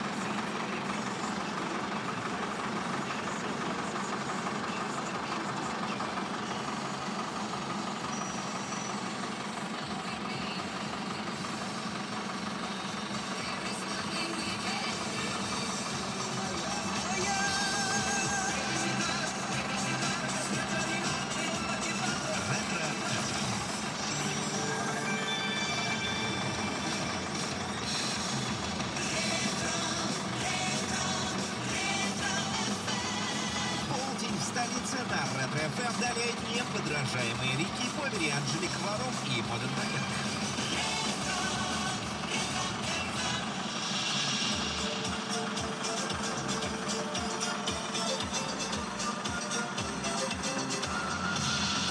Стою в пробке